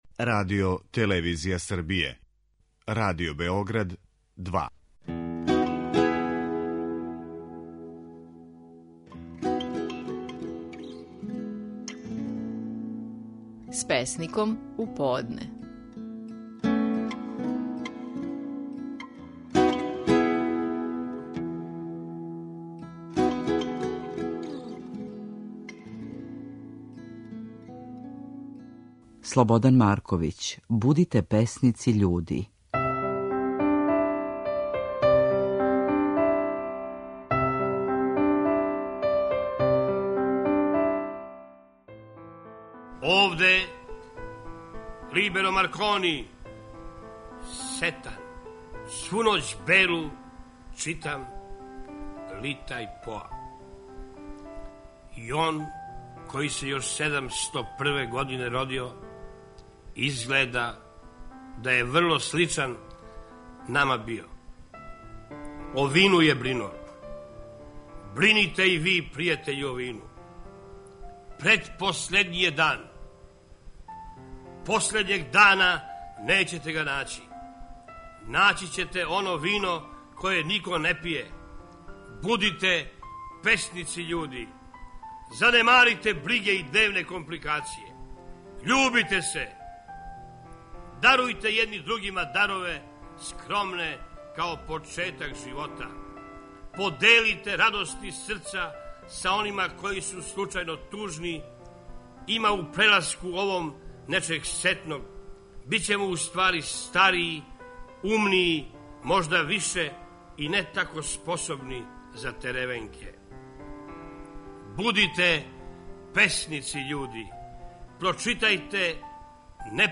Наши најпознатији песници говоре своје стихове
У данашњој емисији, слушаћемо песму Слободана Марковића Будите песници, људи, у казивању самог аутора, познатијег као Либеро Маркони.